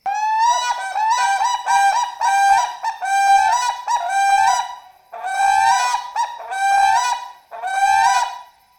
Whooping Crane
Loud, whoop-whoop!
Unison Call | A duet performed by a pair, to strengthen their bond and protect their territory.
Whooping-Crane-Unison.mp3